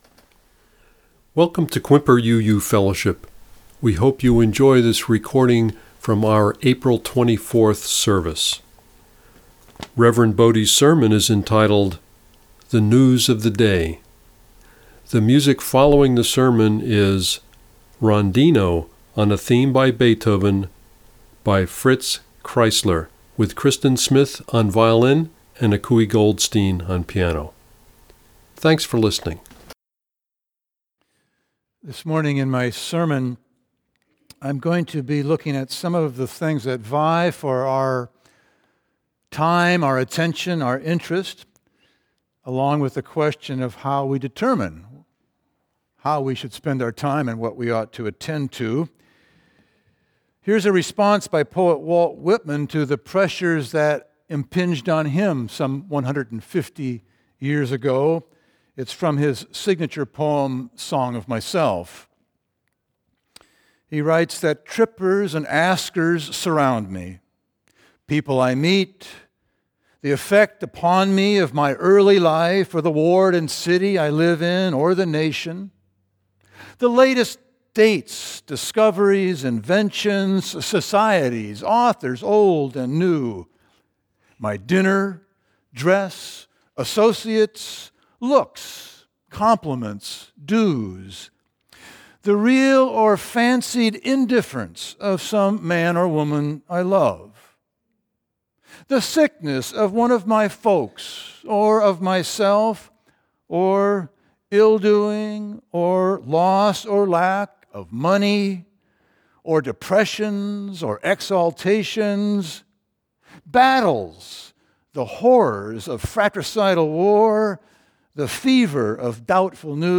In this sermon, I share some of my thoughts on the “news of the day,” and I address the kind of “spiritual discipline” needed to attend to these matters. Click here to listen to the reading and sermon .